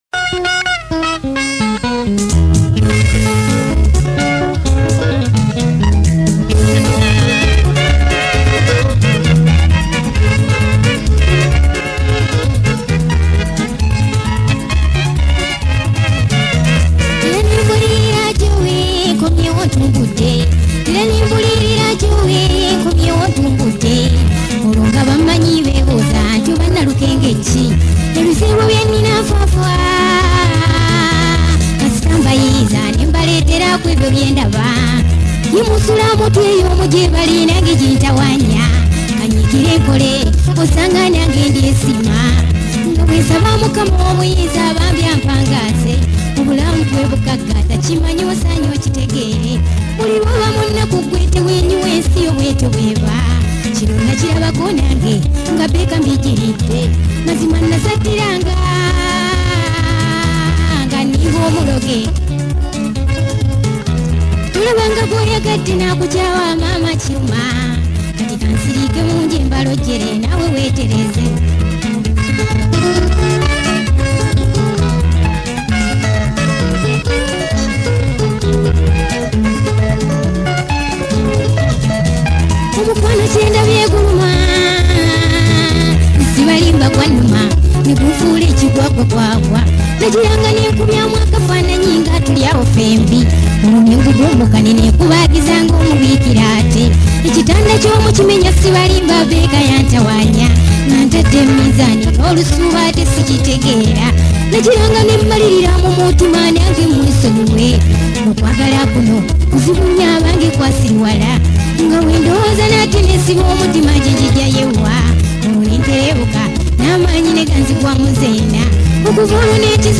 from Kadongo Kamu single's